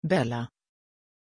Pronuncia di Bella
pronunciation-bella-sv.mp3